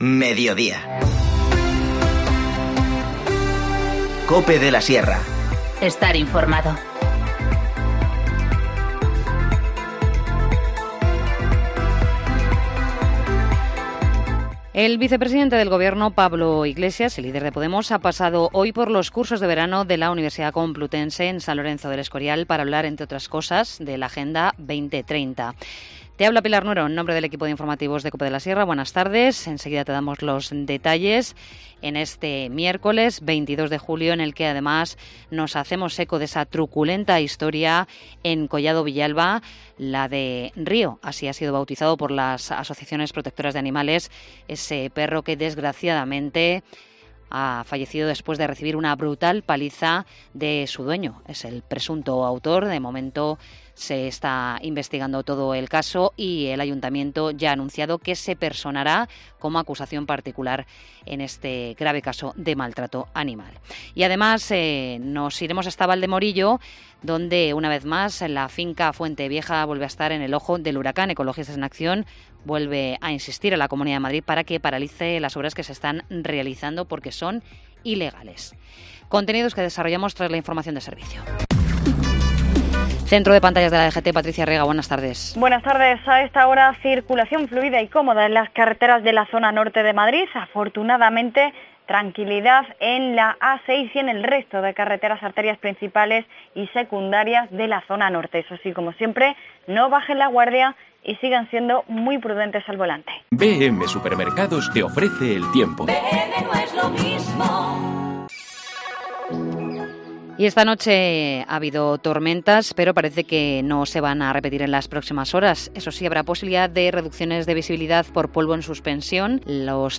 Informativo Mediodía 22 julio
INFORMACIÓN LOCAL